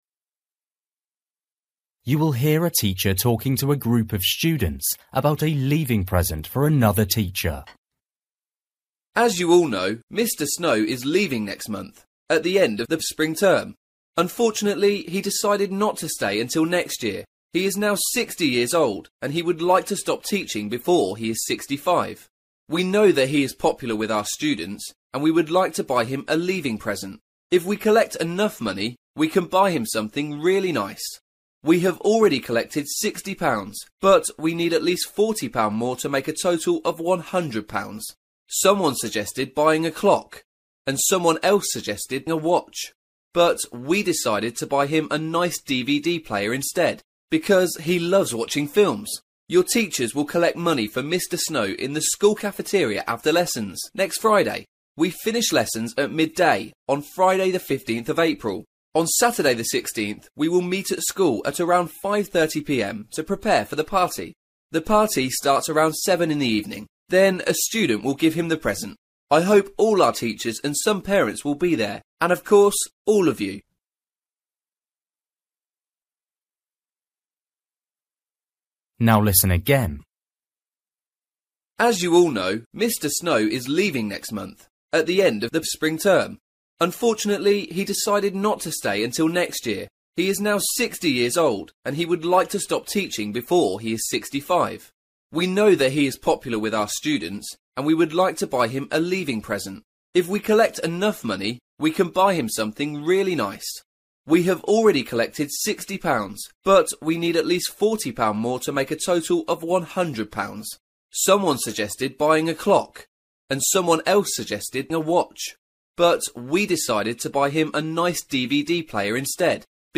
You will hear a teacher talking to a group of students about a leaving present for another teacher.